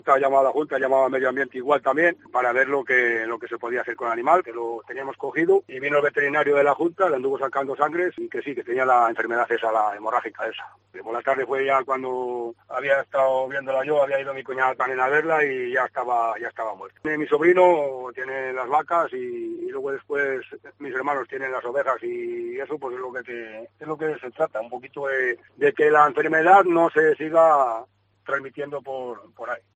El alcalde de Vegalatrave, sobre el ciervo muerto por EHE